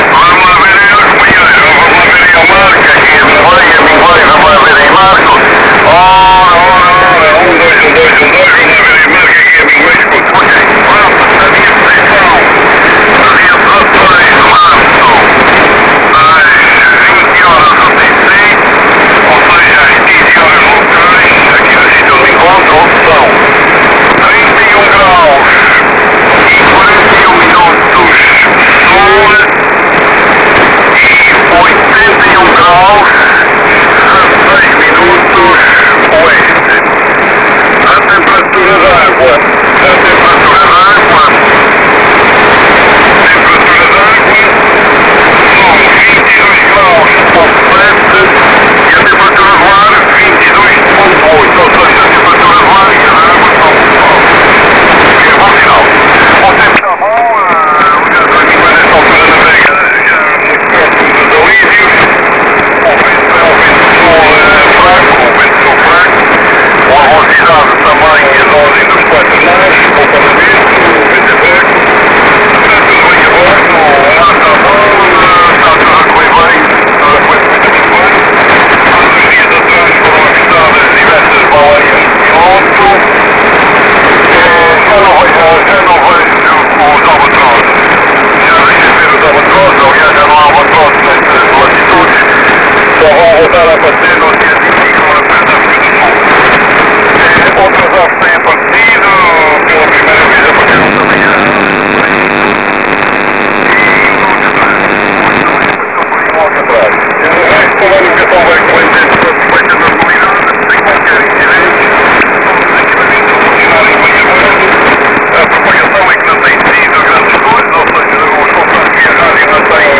Conseguimos estabalecer um belo contacto via rádio que durou 50 minutos.